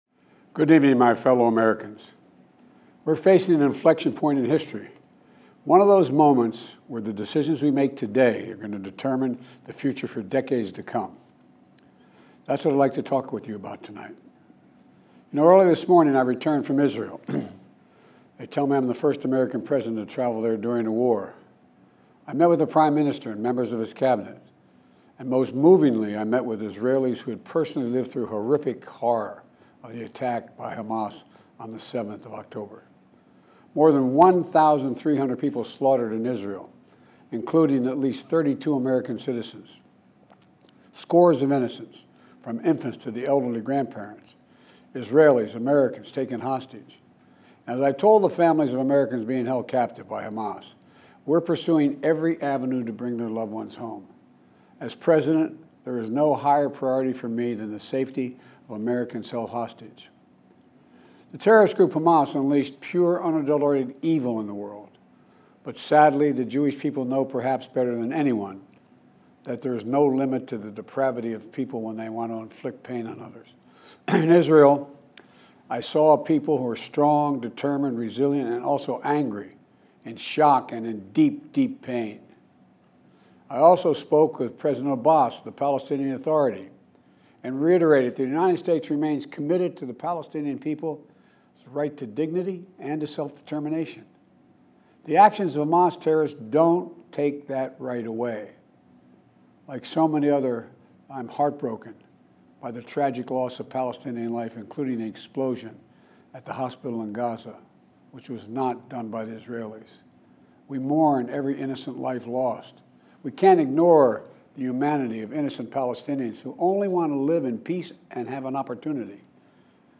President_Biden_Addresses_the_Nation_from_the_Oval_Office.mp3